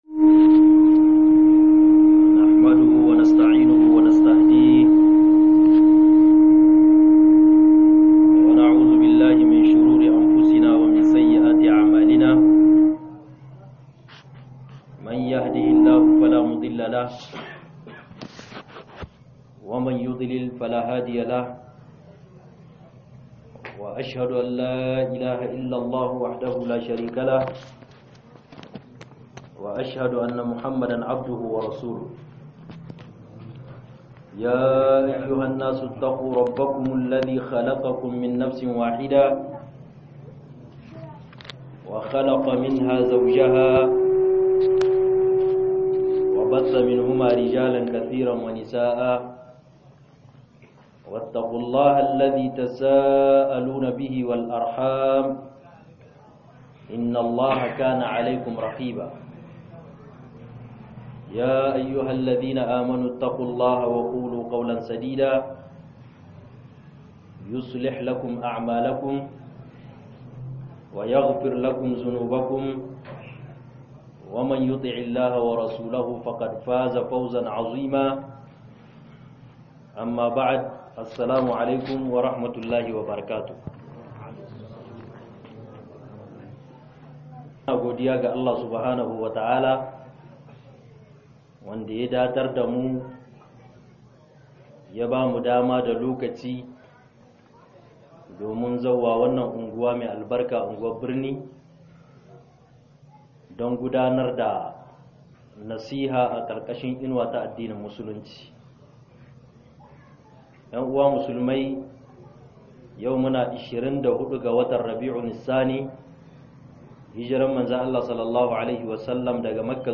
KYEWTATA-ALAKA-DA-UBANGIJI-S.W.T - MUHADARA